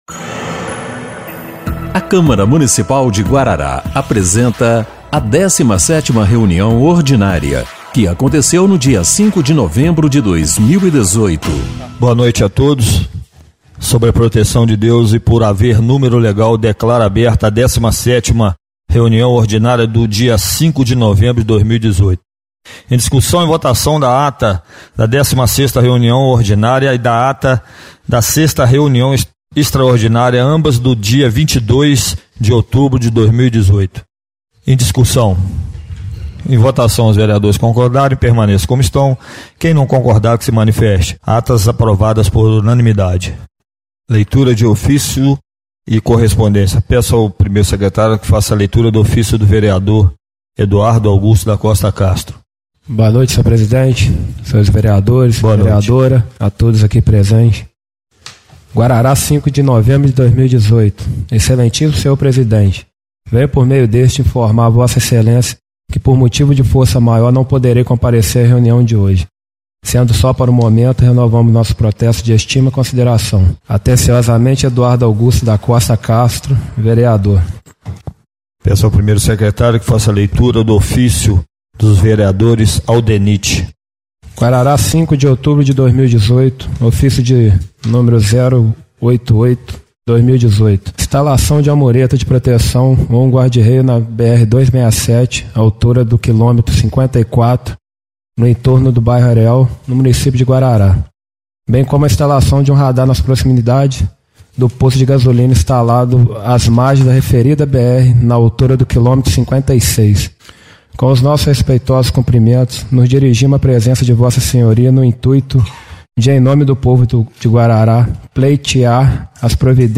17ª Reunião Ordinária de 05/11/2018